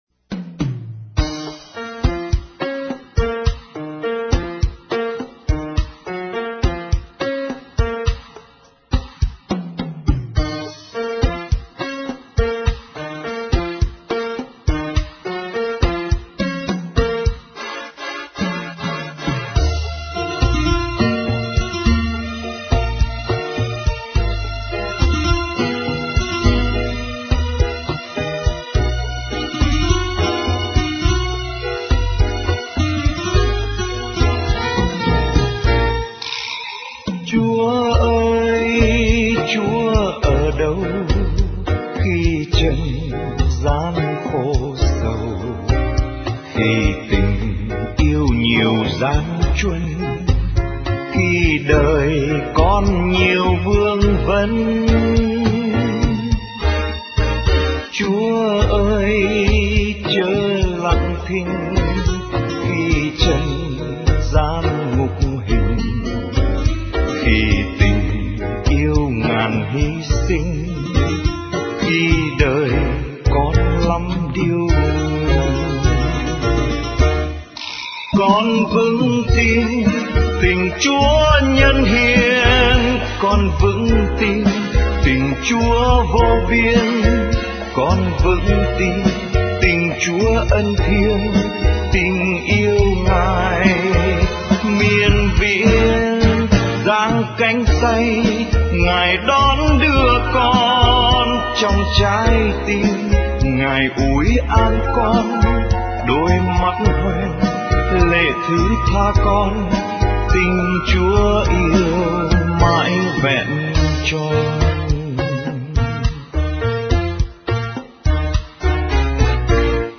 Dòng nhạc : Ngợi ca Thiên Chúa